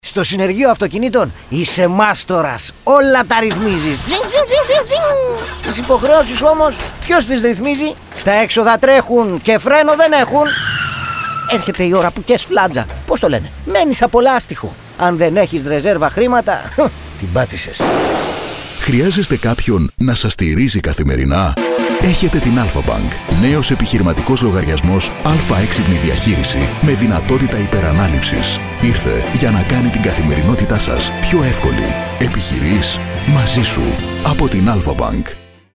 Professional Voice Artist and Voice Coach.
Sprechprobe: eLearning (Muttersprache):